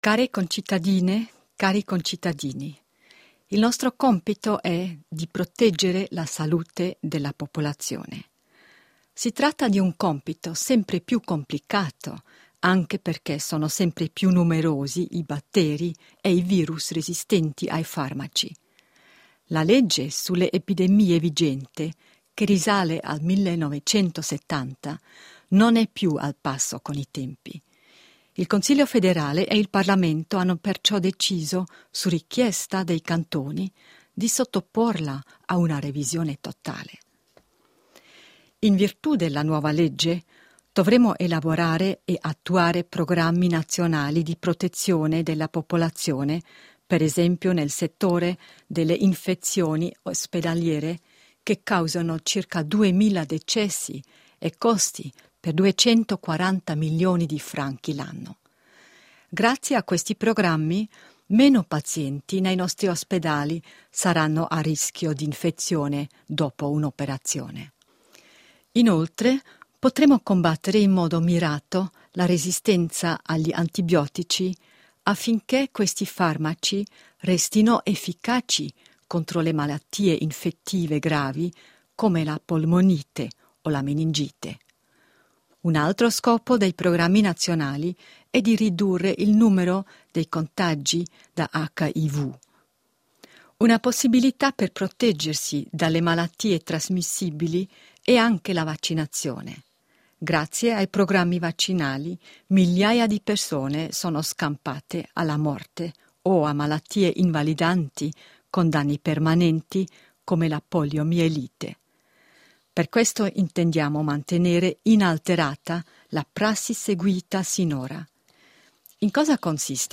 Votazione popolare del 22 settembre 2013 (MP3, 4 MB, 04.09.2013)Dichiarazione del Consiglio federale:
Consigliera federale Simonetta Sommaruga
Conferenza stampa del Consiglio federale del 13.8.2013